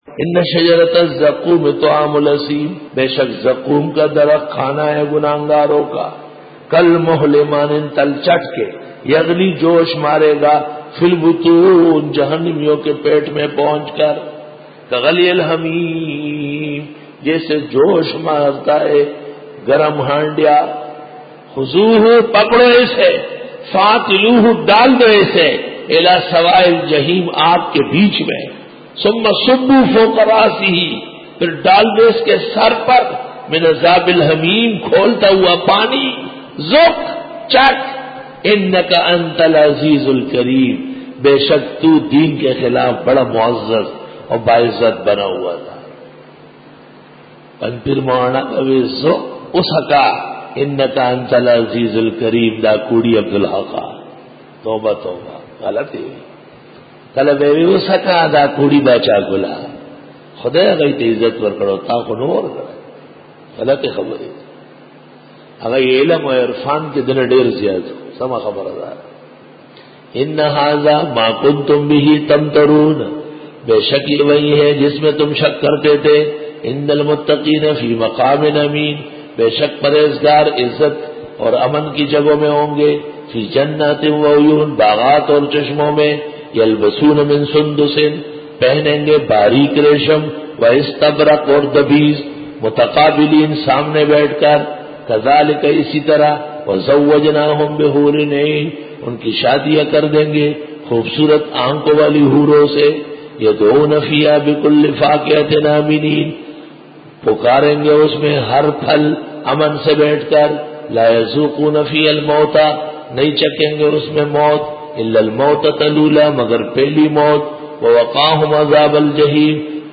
Dora-e-Tafseer 2007